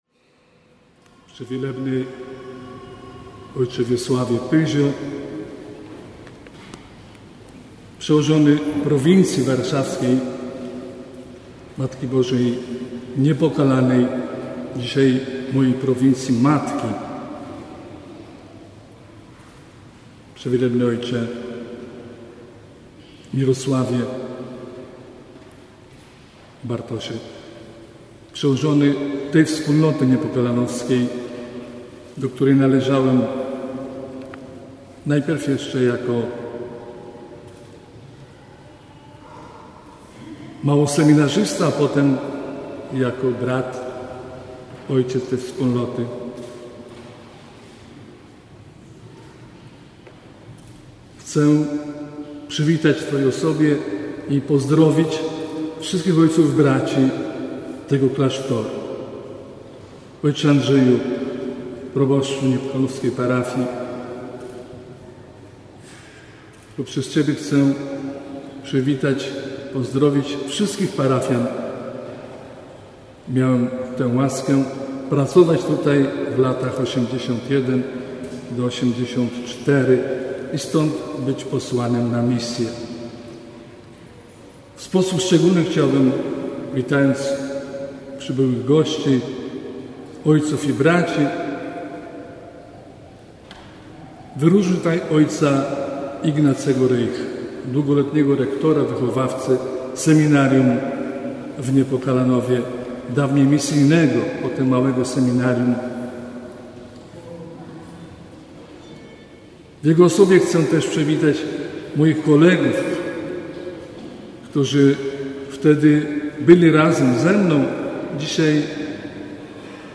W niepokalanowskiej Bazylice, 30 sierpnia 2015 roku, o godz. 10:00 Siostry Franciszkanki Rycerstwa Niepokalanej uczestniczyły w uroczystej Eucharystii,
Homilia o. bp Janusza M. Daneckiego